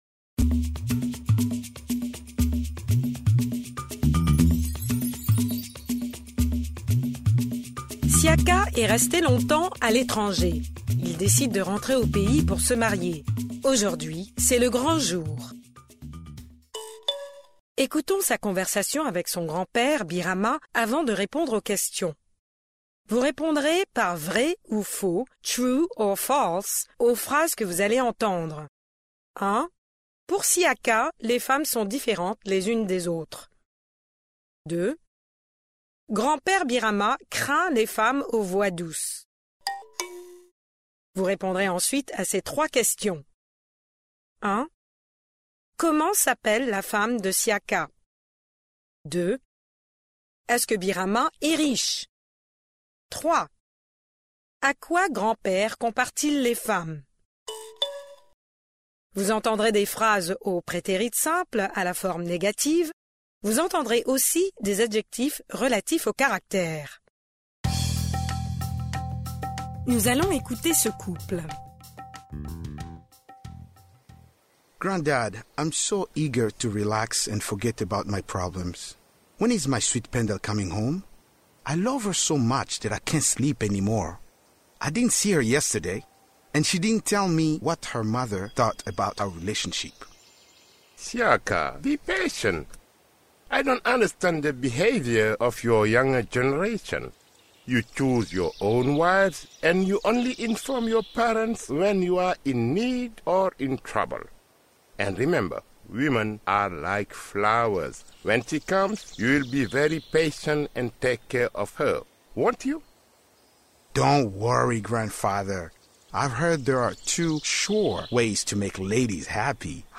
Pre-listening: Ecoutons sa conversation avec son grand-père Birama avant de répondre aux questions.